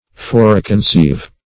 Search Result for " foreconceive" : The Collaborative International Dictionary of English v.0.48: Foreconceive \Fore`con*ceive"\, v. t. To preconceive; to imagine beforehand.
foreconceive.mp3